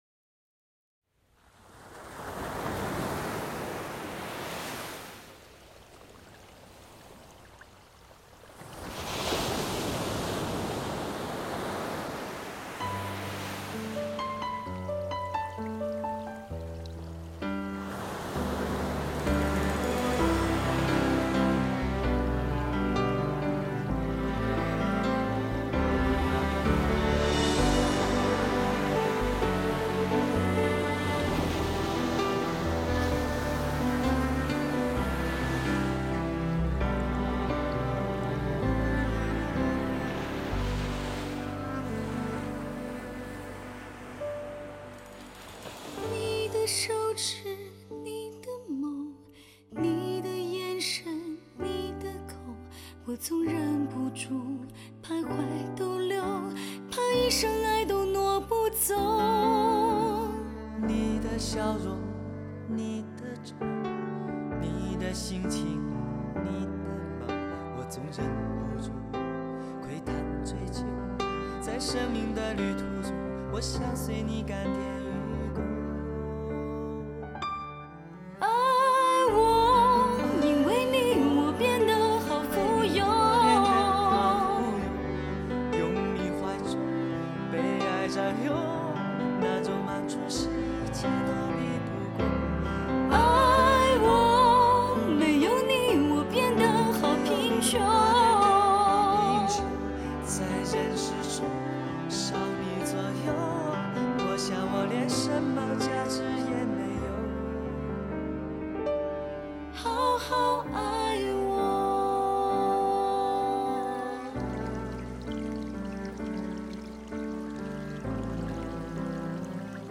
这张专辑只有两首原创歌曲，其他都是翻唱，演唱者唱功都很棒，其中有个声音酷似蔡琴，足可以乱真。录音堪称国内顶级水平。